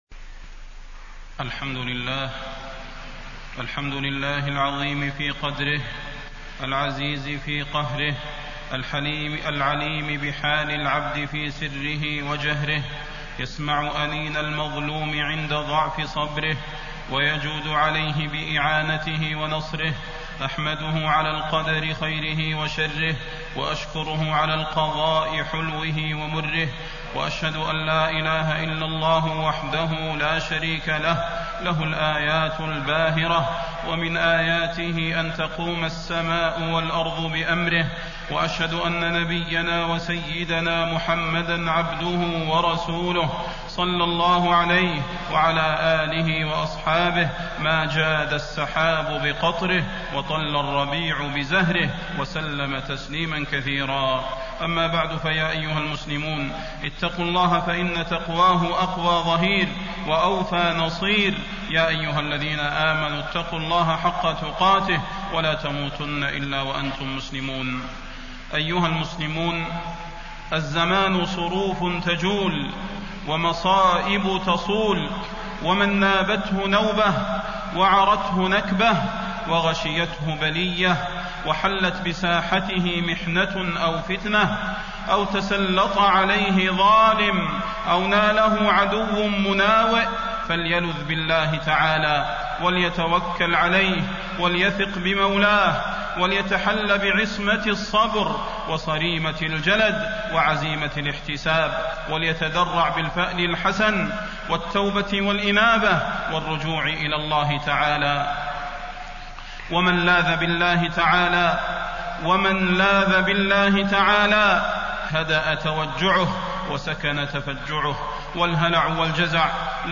تاريخ النشر ١٨ ربيع الأول ١٤٣٣ هـ المكان: المسجد النبوي الشيخ: فضيلة الشيخ د. صلاح بن محمد البدير فضيلة الشيخ د. صلاح بن محمد البدير لا يرفع البلاء إلا الدعاء